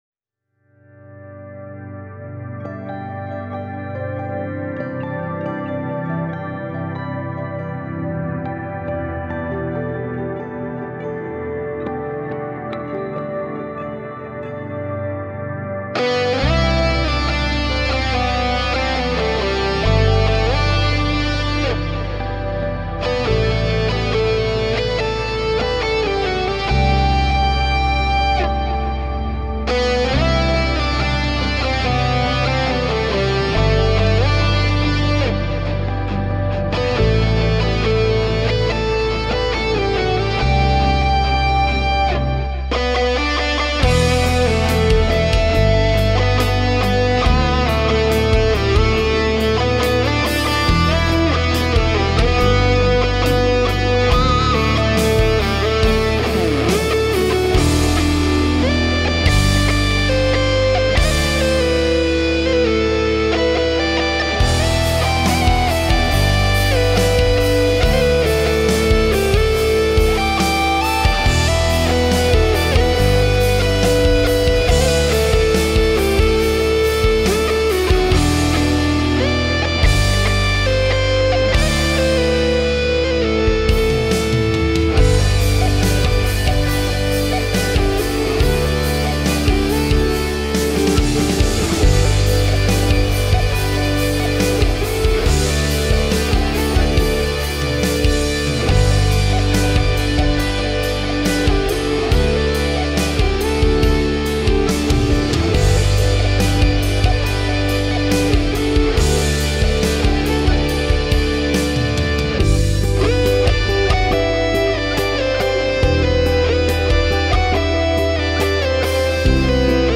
Pop, Rock